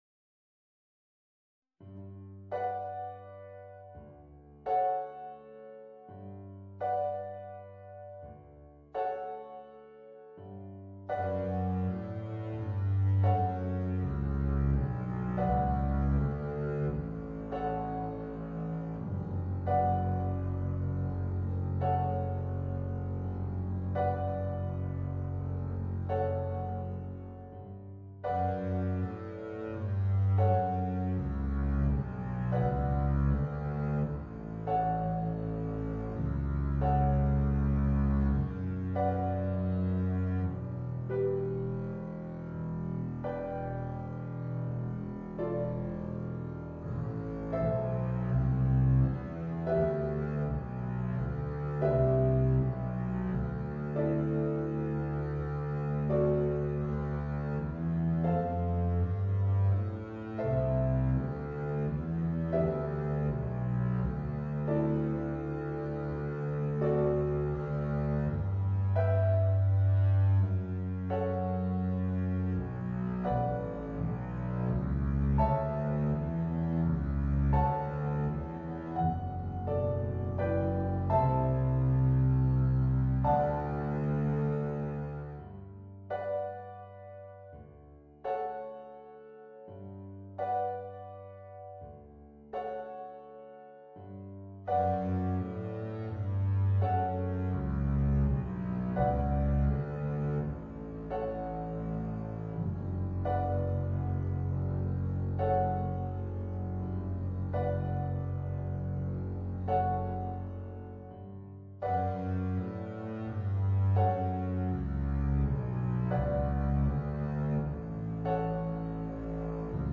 Voicing: Contrabass and Piano